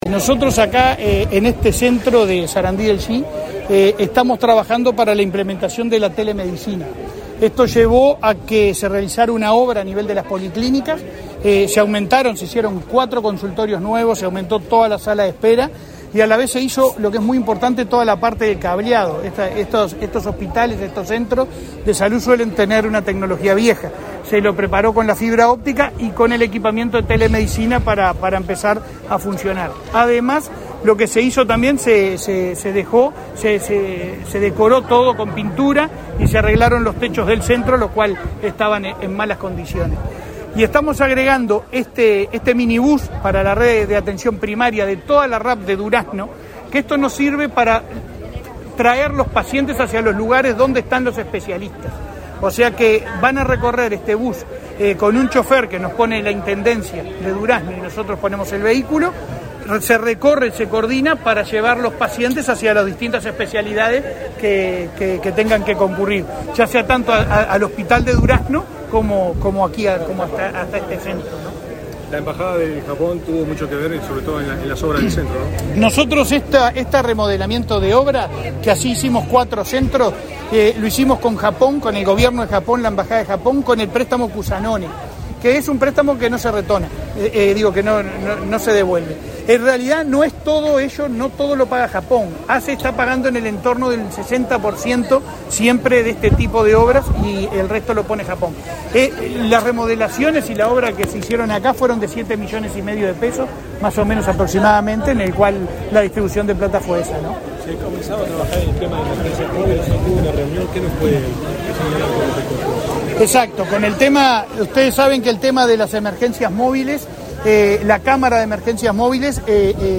Declaraciones del presidente de ASSE, Leonardo Cipriani
Declaraciones del presidente de ASSE, Leonardo Cipriani 13/02/2023 Compartir Facebook X Copiar enlace WhatsApp LinkedIn Tras la inauguración de las obras de remodelación en el Centro Auxiliar de Sarandí del Yí, se expresó el presidente de la Administración de los Servicios de Salud del Estado (ASSE), Leonardo Cipriani.